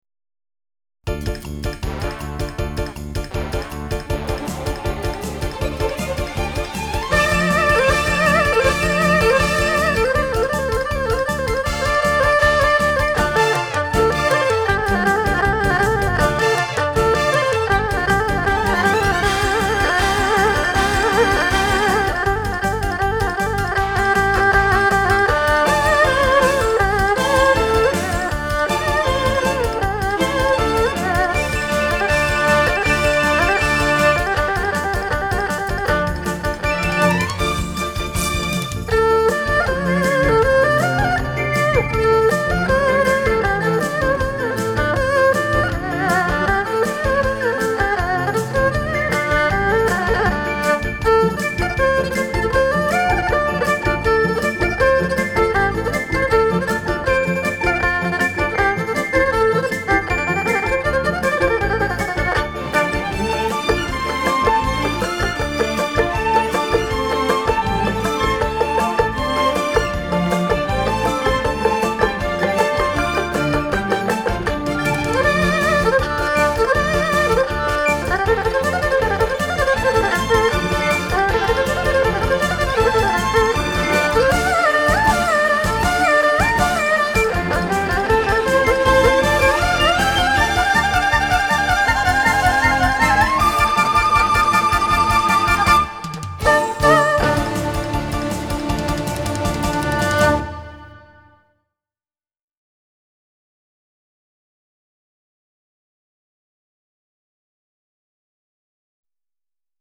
二胡、中胡独奏
同时，拨奏和连奏的技巧运用，使乐曲显得更加生动活泼。
乐曲的最后，以第一段旋律的变化再现结束全曲。